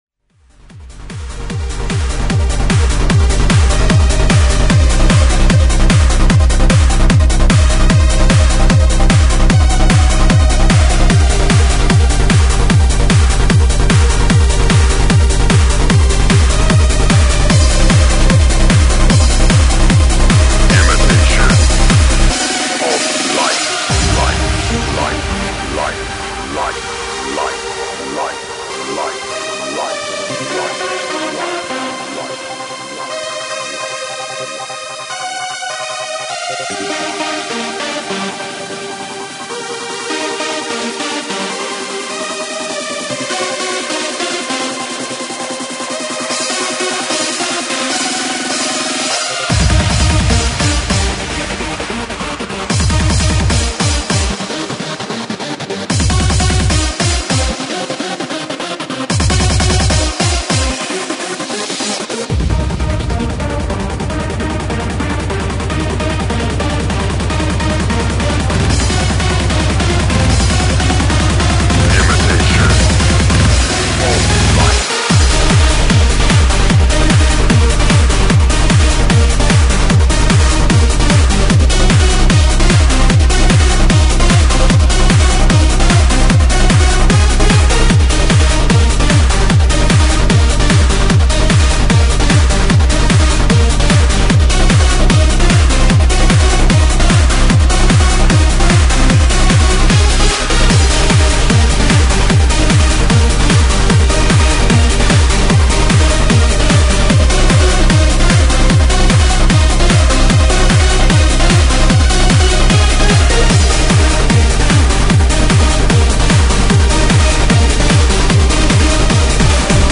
Hard House/Hard Trance/Freeform